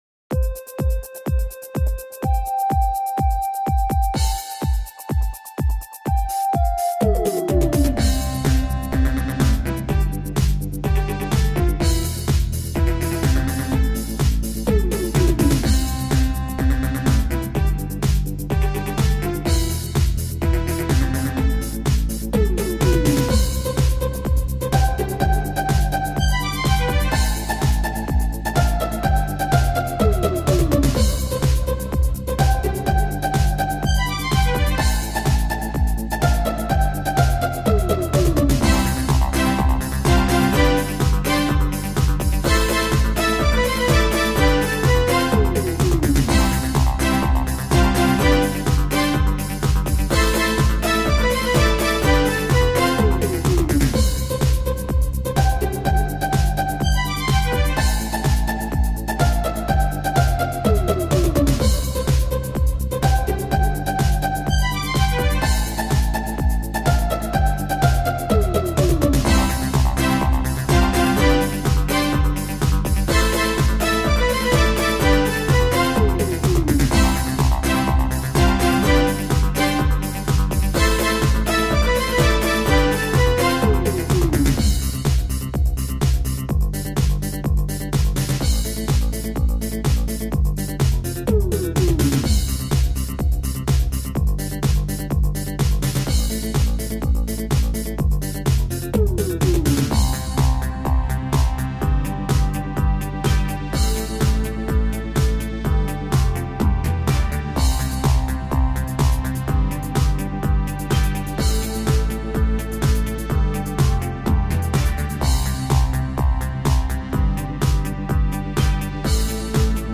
• Жанр: Танцевальная
Europop / Synth-pop. 2003.